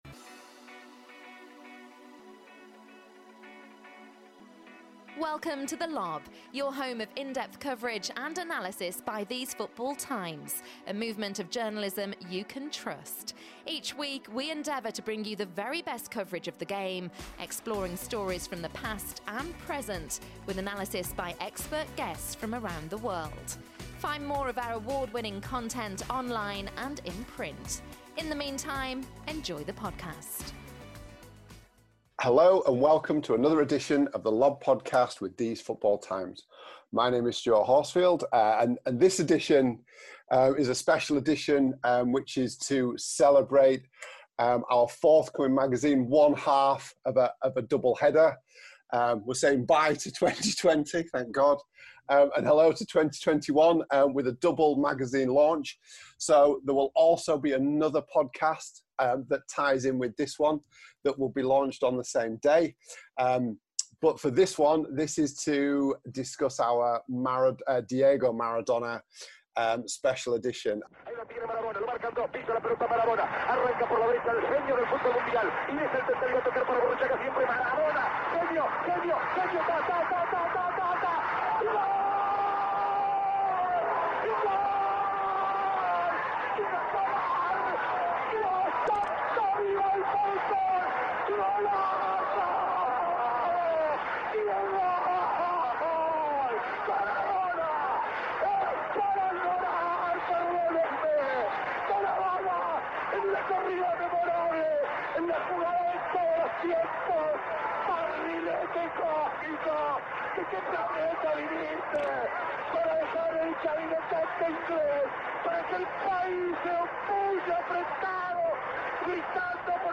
The podcast team sit down to discuss the life and time of Maradona ahead of the tribute magazine release.